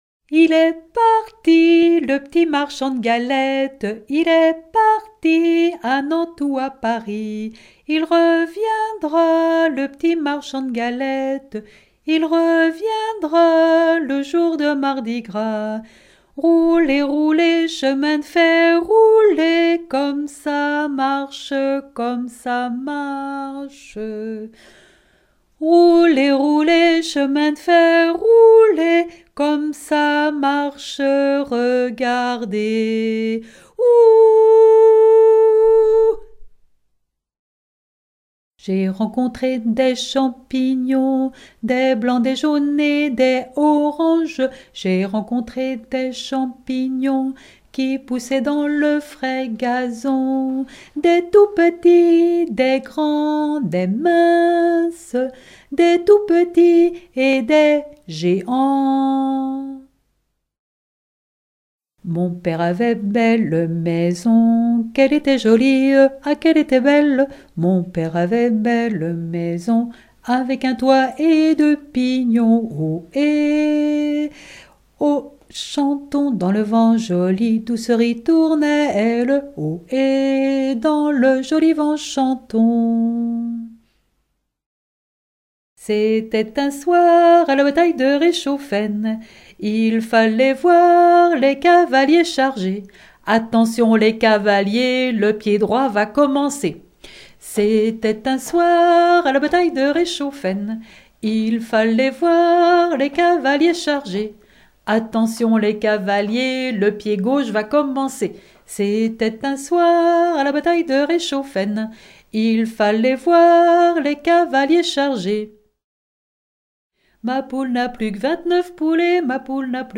Suite de chants d'école
Enfantines - rondes et jeux